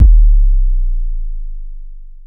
Waka KICK Edited (40).wav